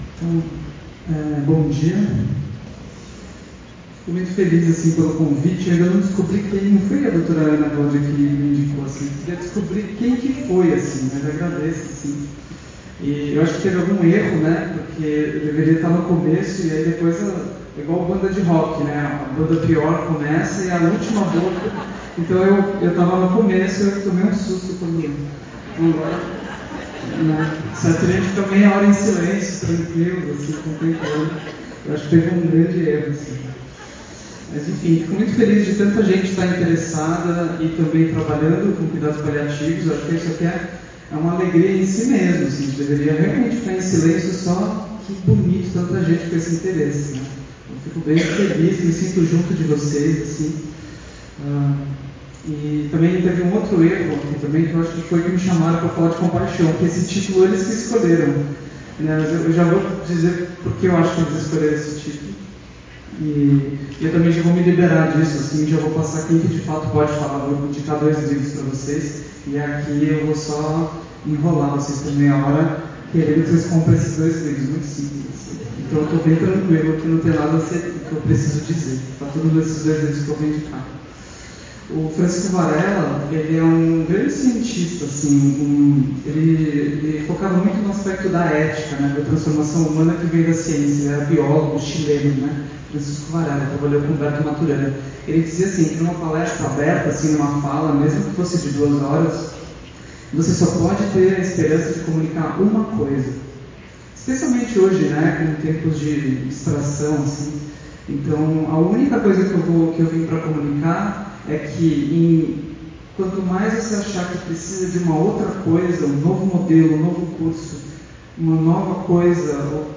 Aqui está o PDF da apresentação ↓ e aqui o áudio em MP3 para download ↓ (a gravação está ruim, mas dá para entender).